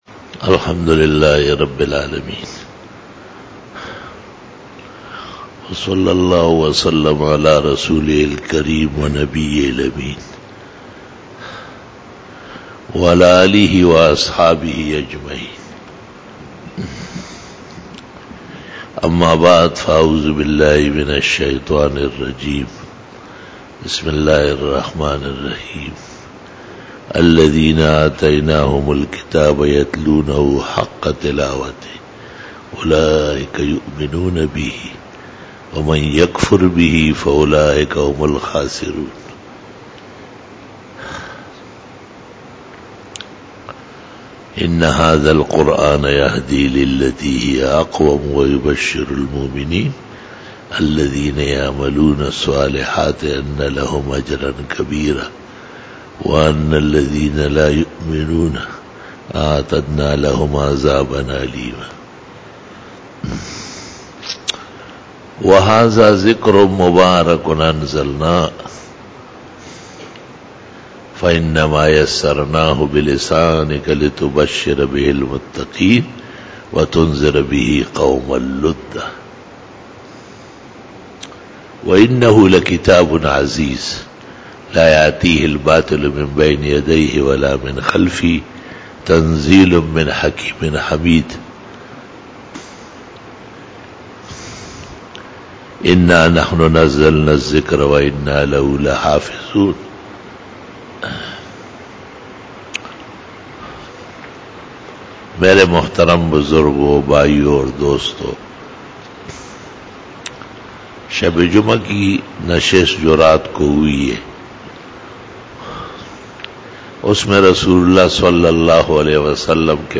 09 BAYAN E JUMA TUL MUBARAK 03 MARCH 2017 (03 Jamadi us Sani 1438H)
Khitab-e-Jummah